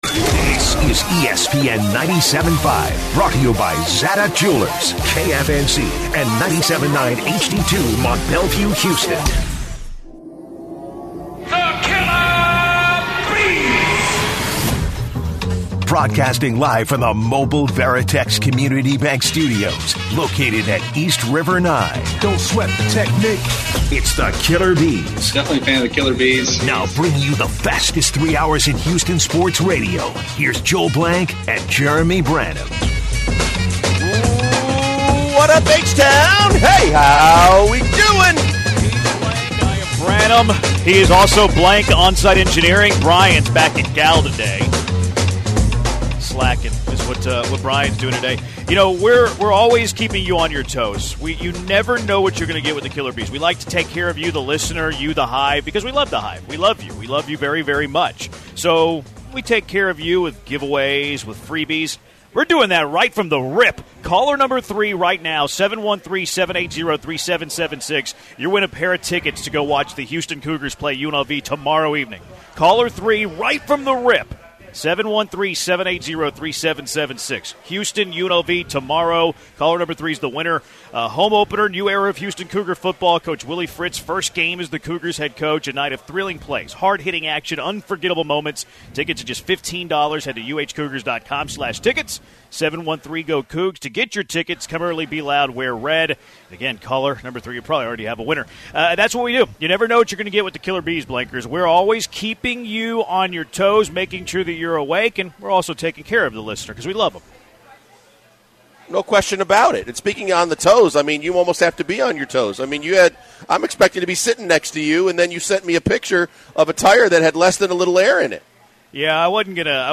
LIVE from East River 9!